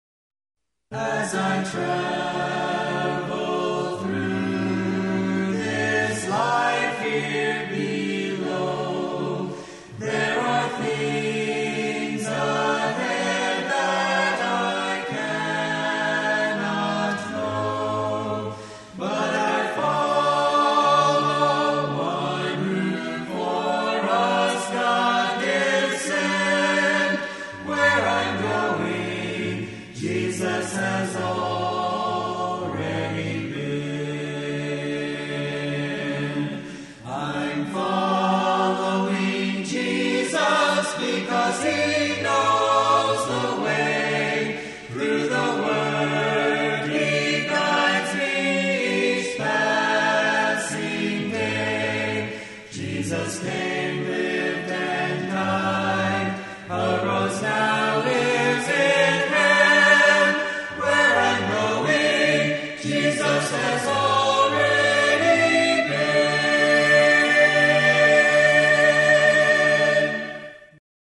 Soprano
Altos
Tenor
Bass